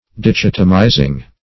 Dichotomize \Di*chot"o*mize\, v. t. [imp. & p. p.